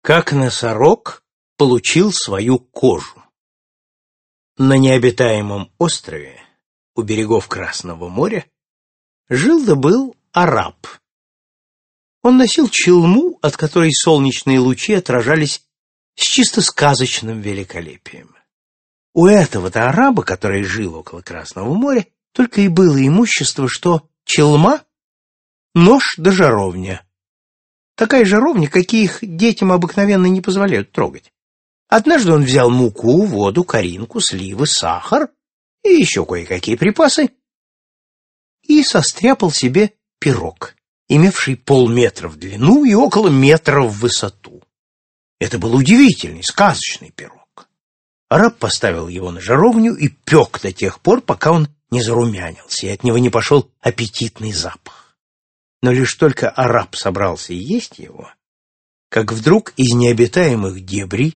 Aудиокнига Сказки Автор Редьярд Джозеф Киплинг